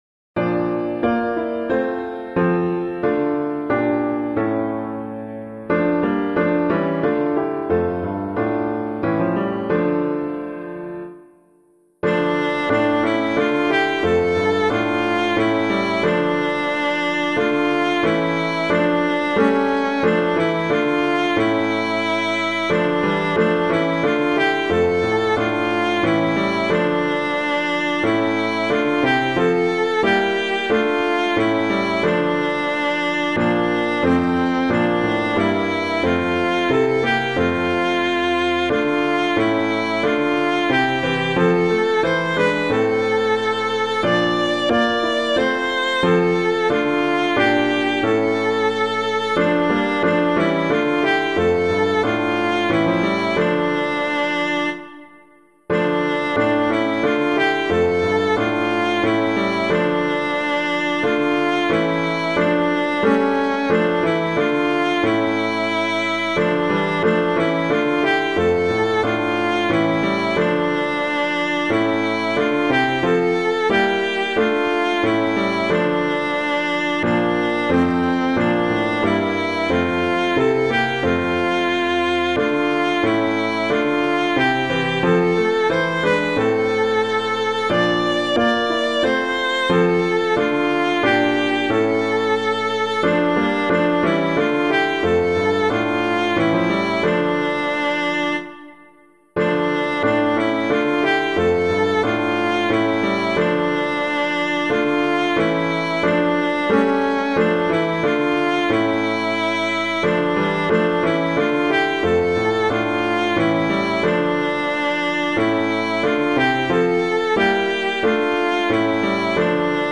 piano
Man of Sorrows Wrapt in Grief [Bridges - ABERYSTWYTH] - piano.mp3